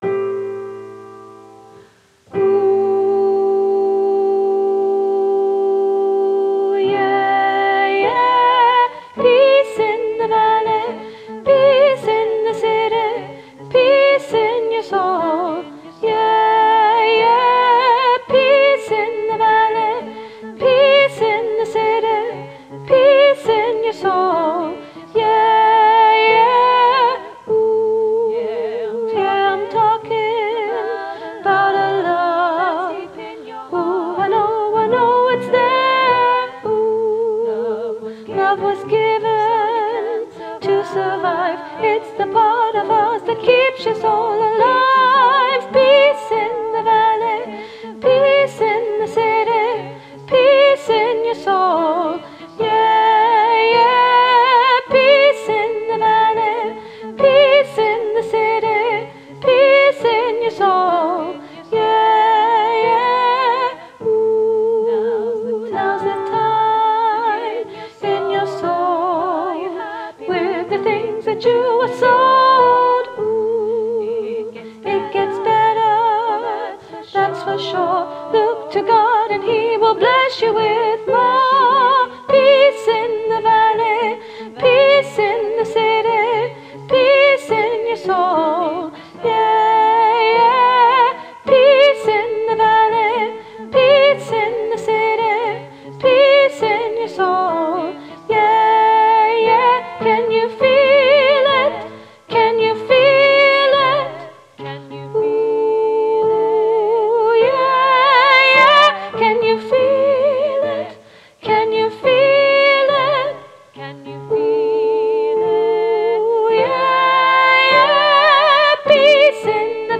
Peace-Soprano-Lead - Three Valleys Gospel Choir